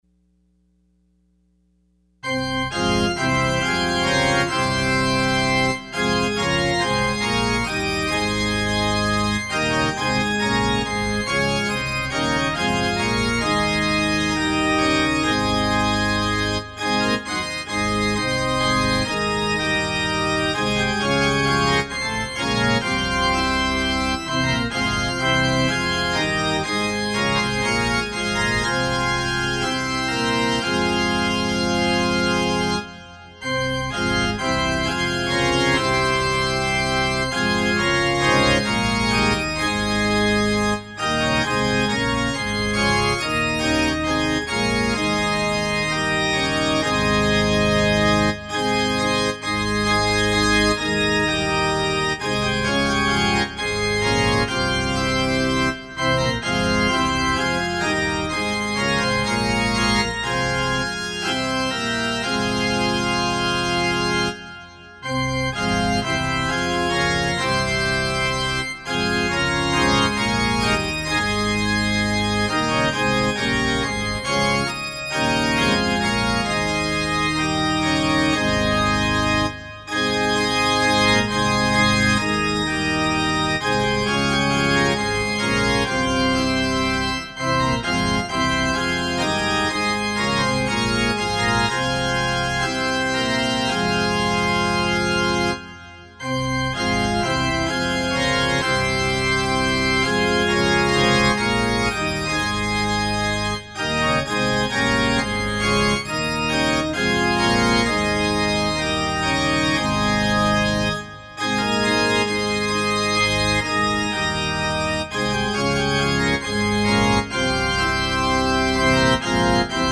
Opening Hymn – Praise to the living God #372